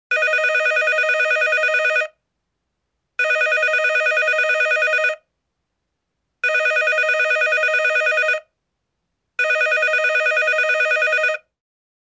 Звуки смартфона Самсунг
Звон офисного телефона